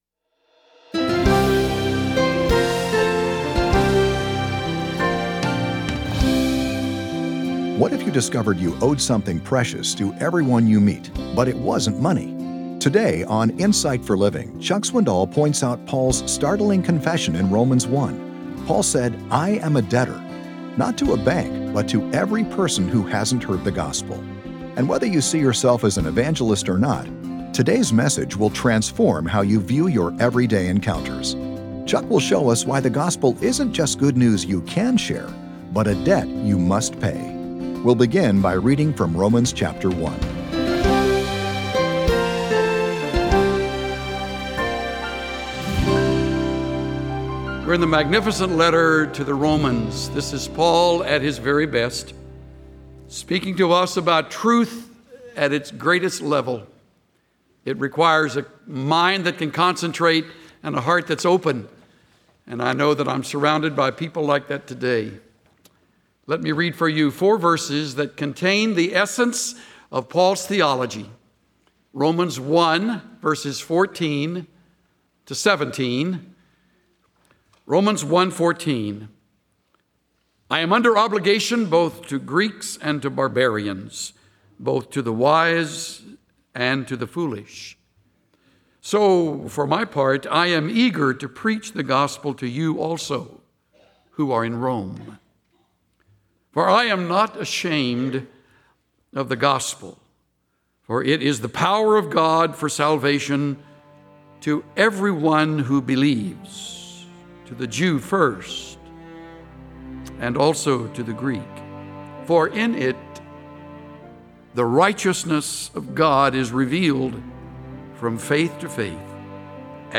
Join the millions who listen to the lively messages of Chuck Swindoll, a down-to-earth pastor who communicates God’s truth in understandable and practical terms—with a good dose of humor thrown in. Chuck’s messages help you apply the Bible to your own life.